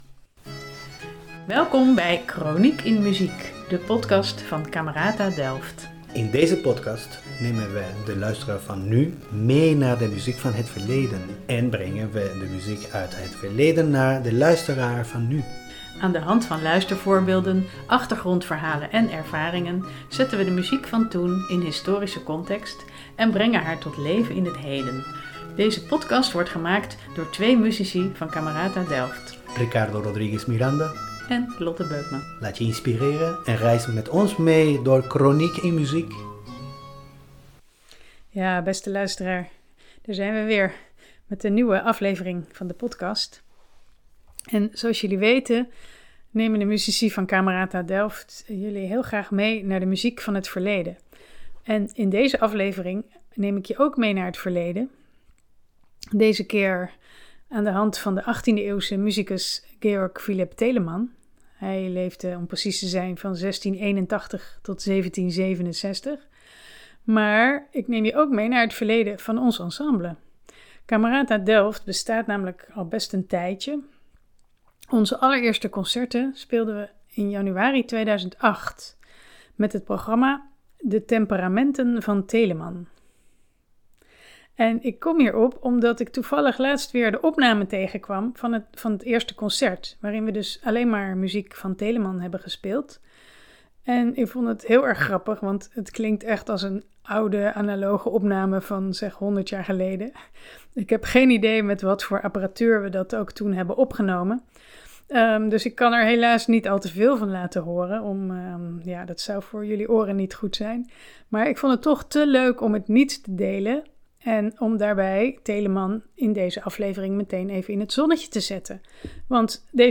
Muziek van Telemann komt regelmatig terug in onze concerten, dus je hoort, naast luistervoorbeelden van dat allereerste concert, ook enkele opnames van recenter datum. Daarnaast belichten we met feiten en anekdotes leven en werk van deze veelzijdige allemansvriend.